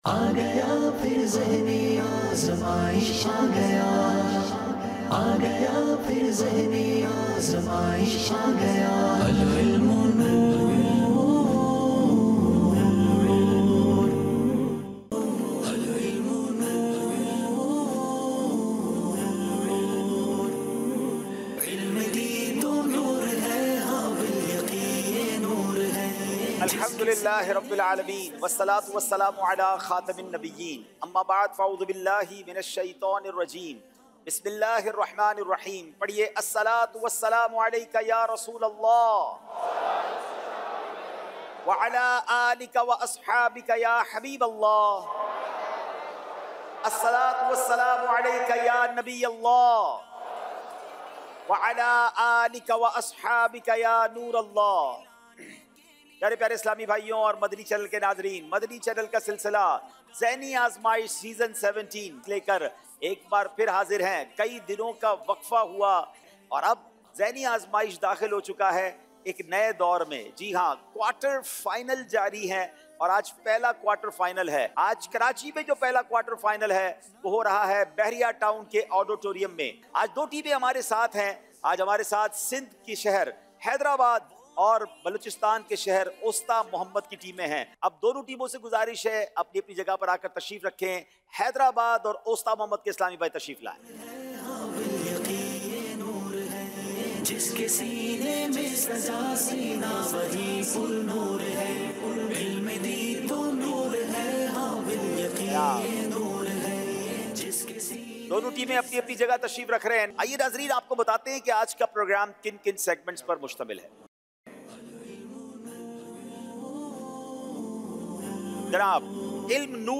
Question & Answer Session